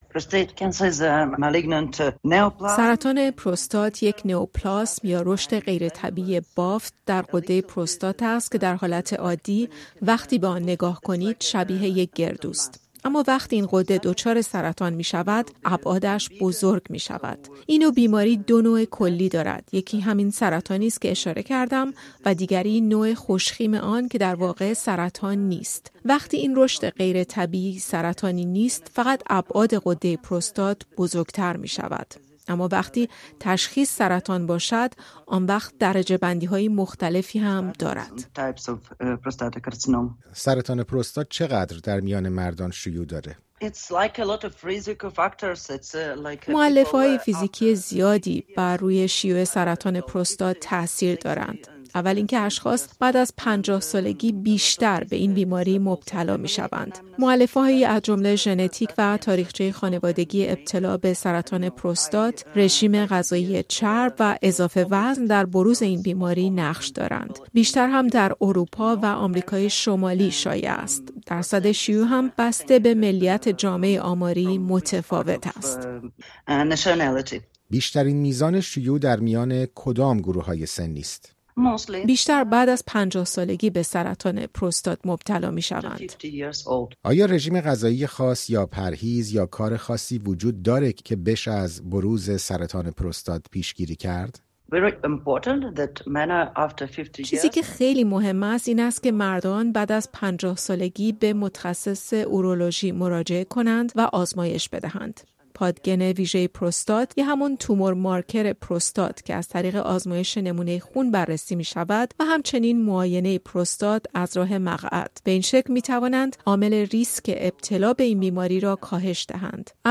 در گفت‌وگو با رادیو فردا نخست دربارهٔ نحوه بروز سرطان پروستات توضیح داده است.